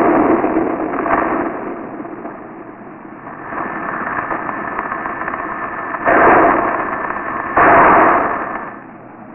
Mars Microphone: Test Sound Data (Earthquake)
earthqua.wav